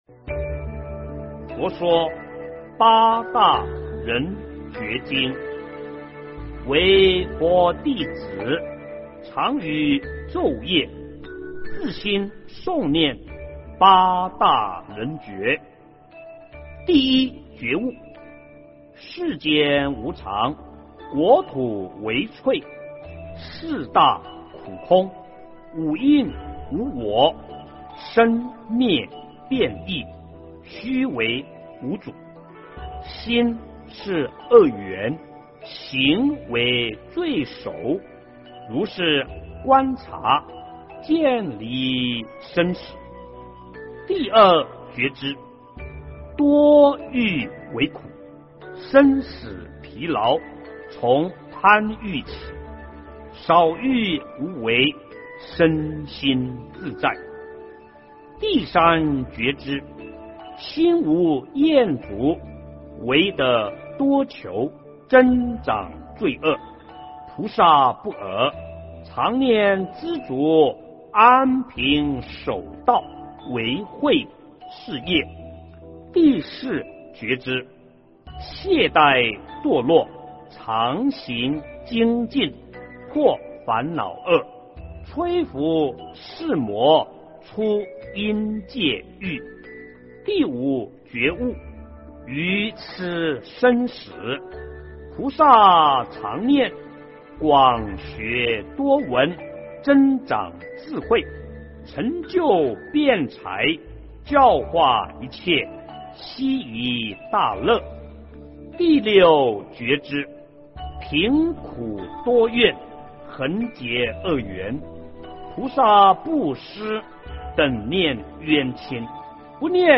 佛说八大人觉经 - 诵经 - 云佛论坛